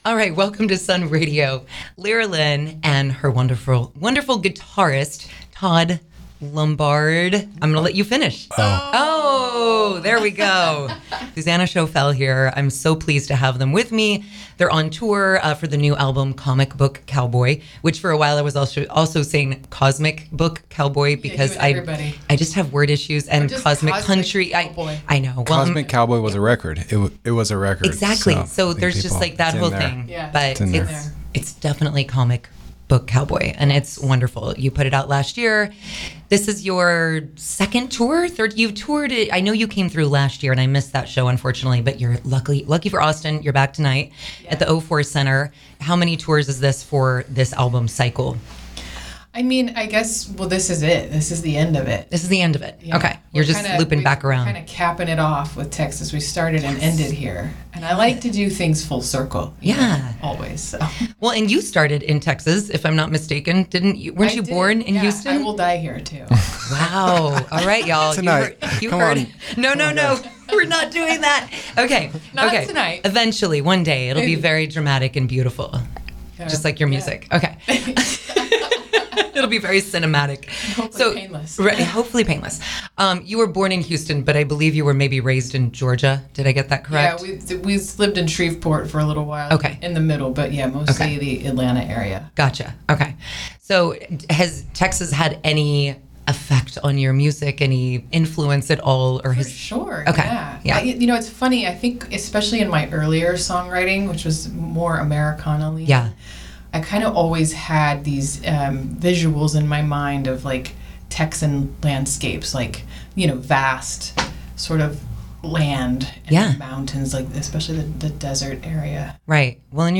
Lera Lynn in Sun Radio Studios
While she was in the Sun Radio studios she also performed the songs Cherry Tree and Digital You . Listen below to her in-studio interview and performances:
lera-lynn-interview-web.mp3